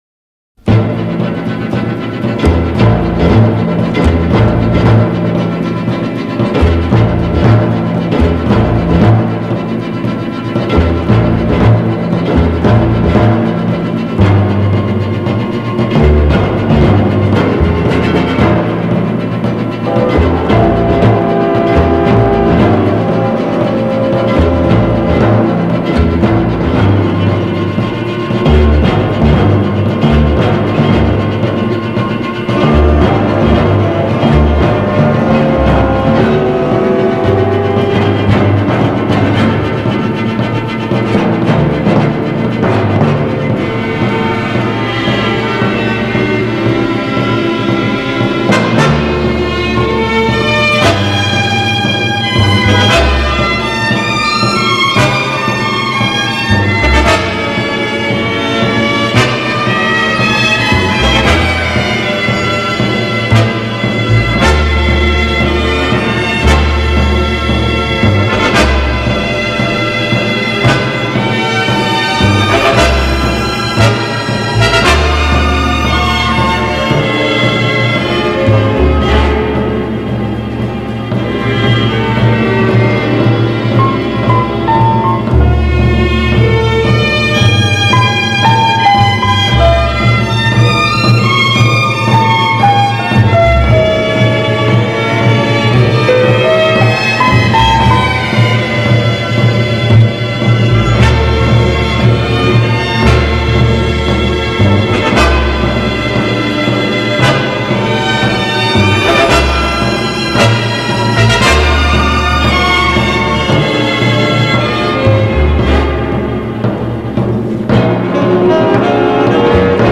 (orch.)